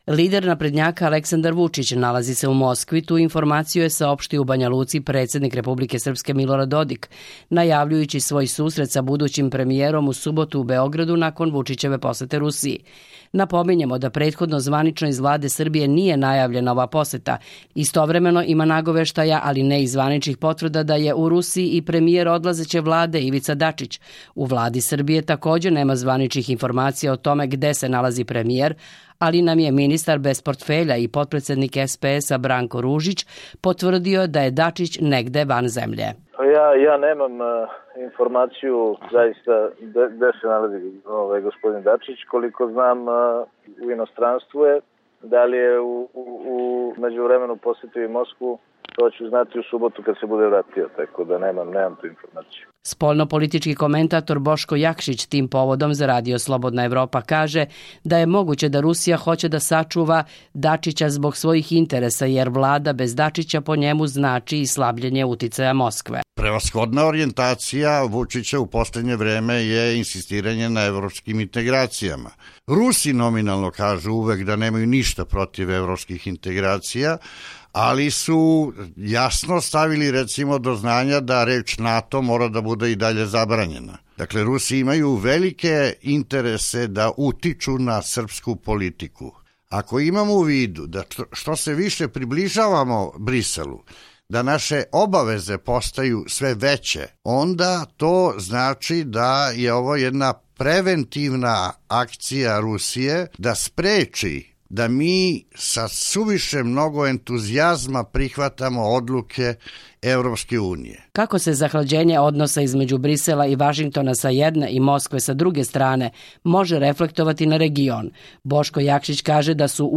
Intervju Boško Jakšić